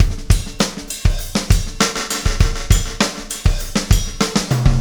Pulsar Beat 09.wav